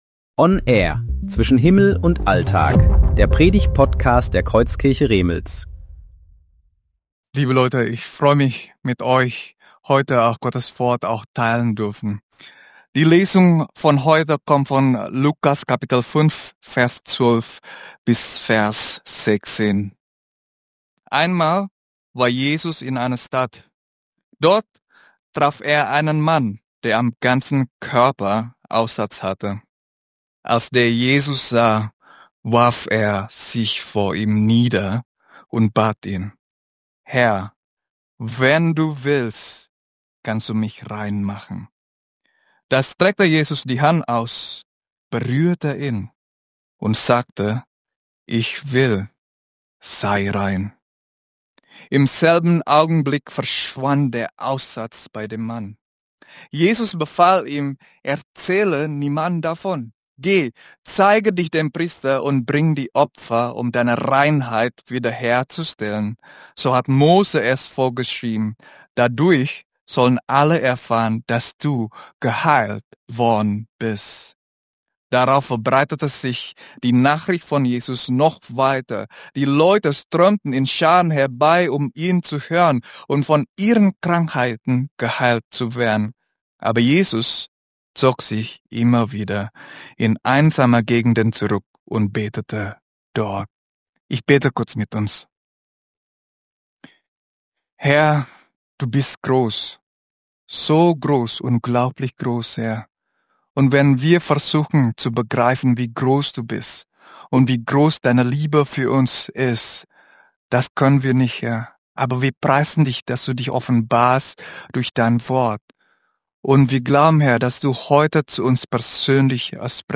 Predigten
Predigtserie: Gottesdienst